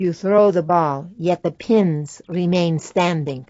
gutterball-3/Gutterball 3/Commentators/Natasha/nat_throwballpinstands.wav at fceb2d37df47dc12bc660c23fa1f6ad4d1ce774d
nat_throwballpinstands.wav